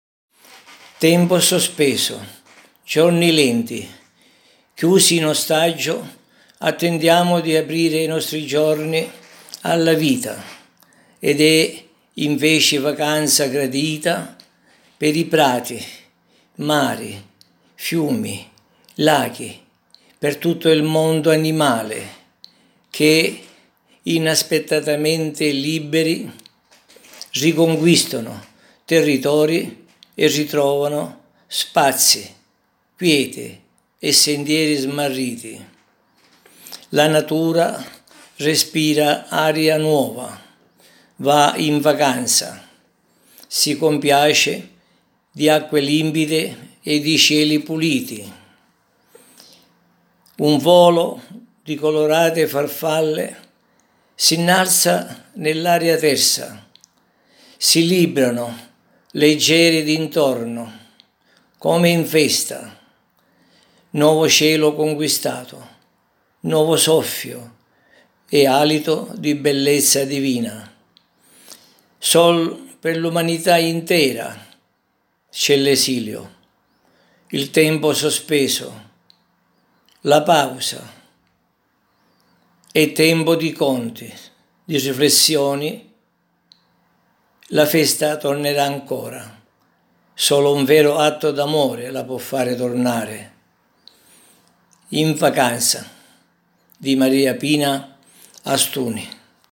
interpreta la poesia